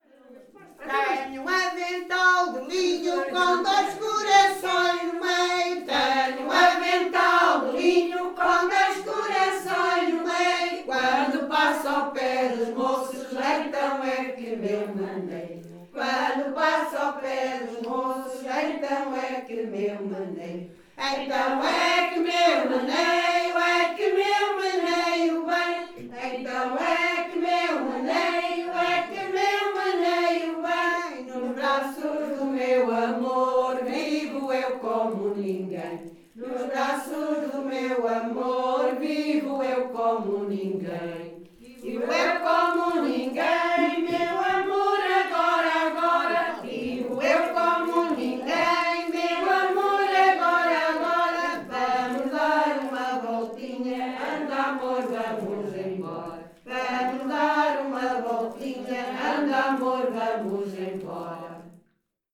NODAR.00704 – Grupo Etnográfico de Trajes e Cantares do Linho de Várzea de Calde – Ensaio – Tenho um avental de linho (Várzea de Calde, Viseu)